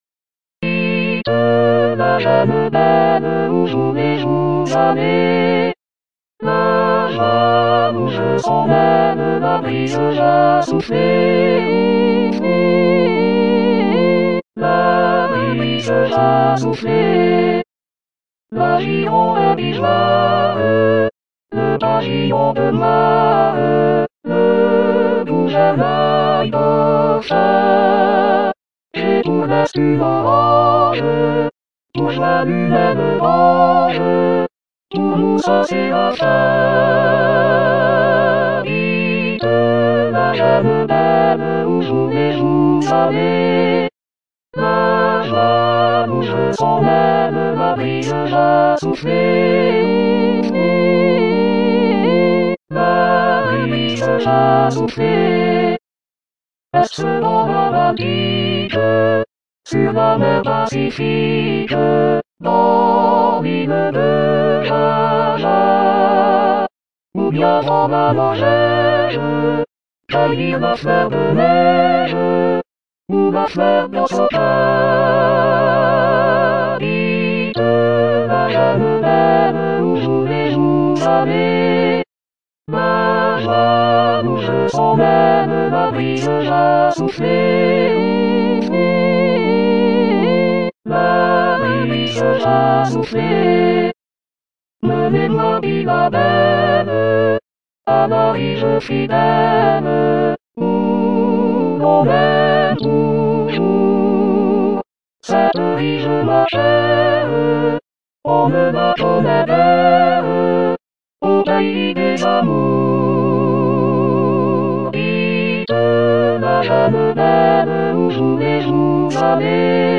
Gounod-01-Choeur.mp3